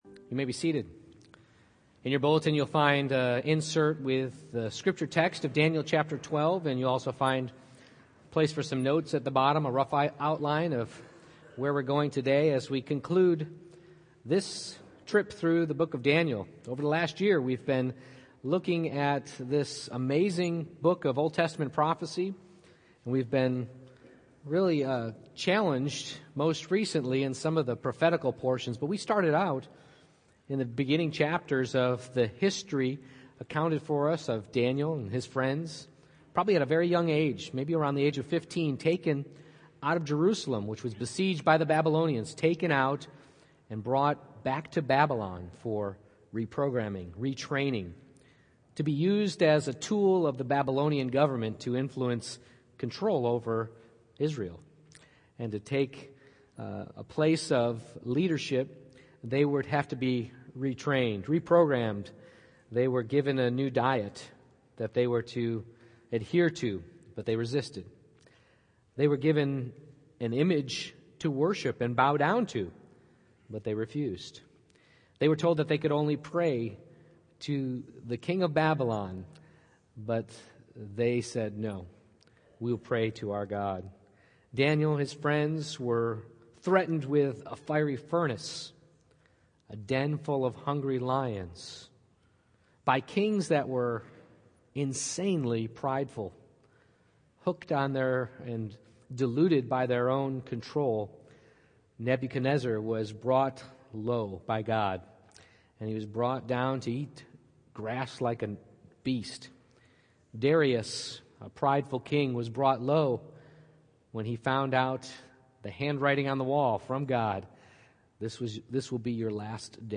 Daniel 12:1-13 Service Type: Morning Worship God alone knows the future